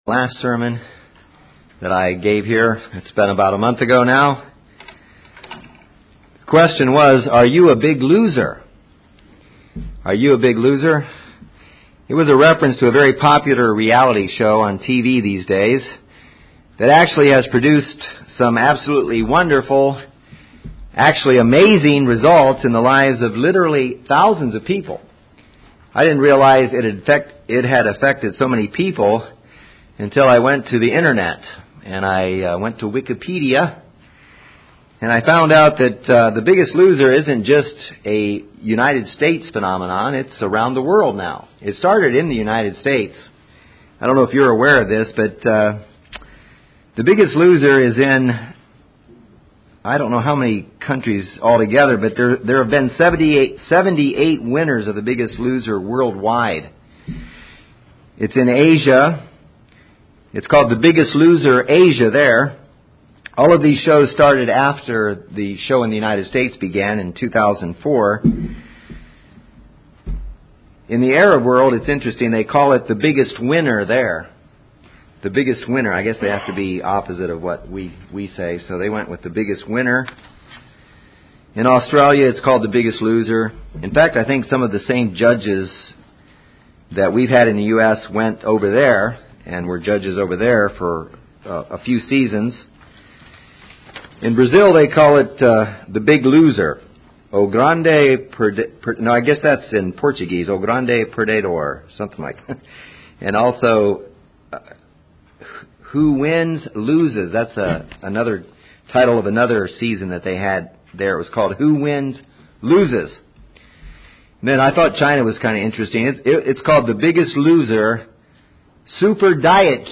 In this series of sermons you will find seven steps to becoming spiritually lean and fit.